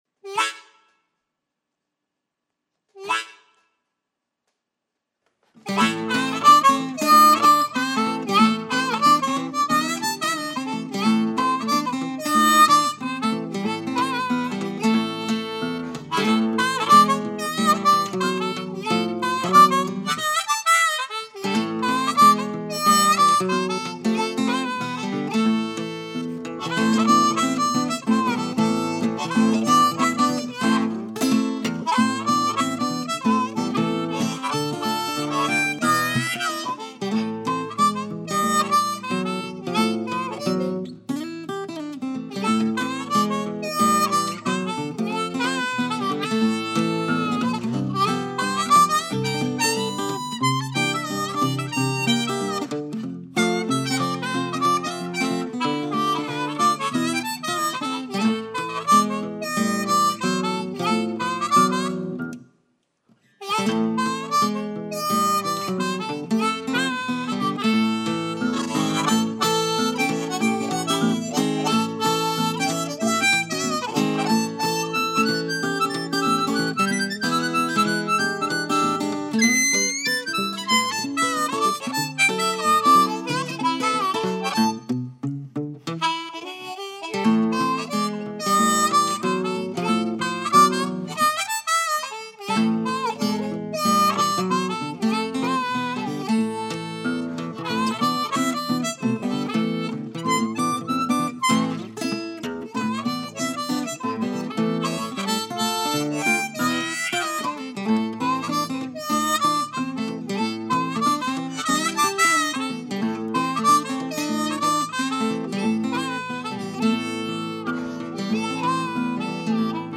Standard Richter diatonic harmonicas.
guitar